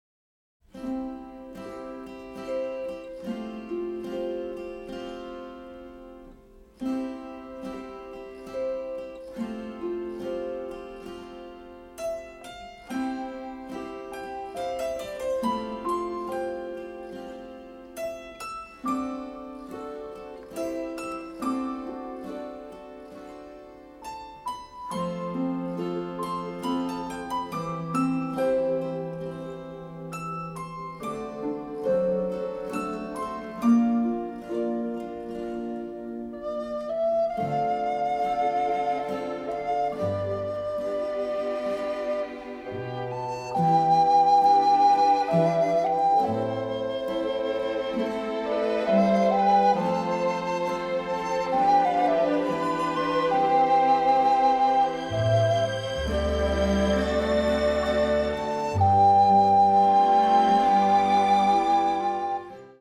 using a single theme